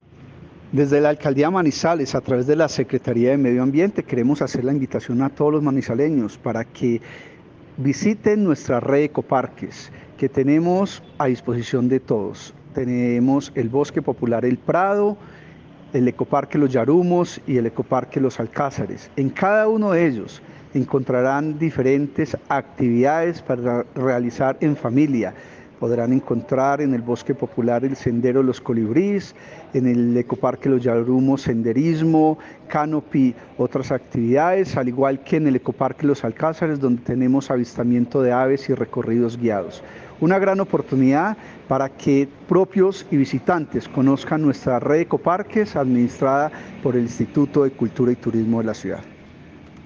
Carloman Londoño Llano, secretario de Medio Ambiente.